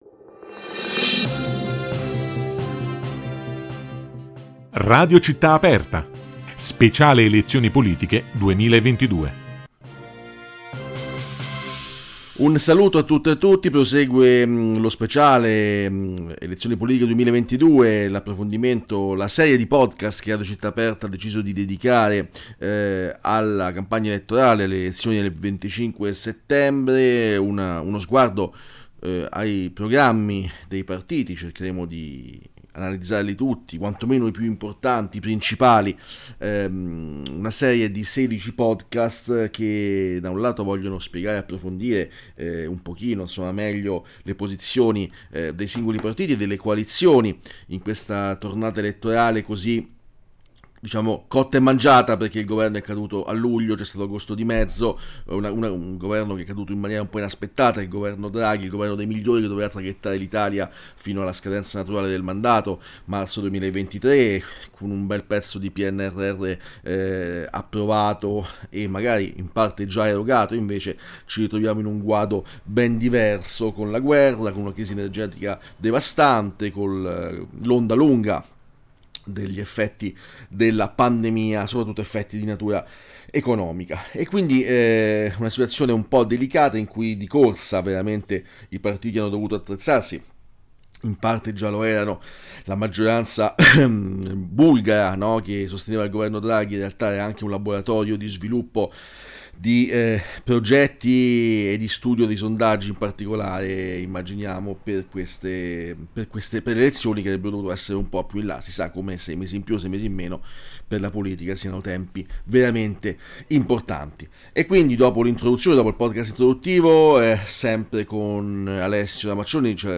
La redazione giornalistica di Radio Città Aperta accompagna i propri ascoltatori verso le elezioni politiche 2022,e lo fa con una serie di podcast in cui i giornalisti RCA commentano ogni giorno i singoli programmi dei partiti e, in generale, dei diversi schieramenti che si sfideranno il prossimo 25 settembre.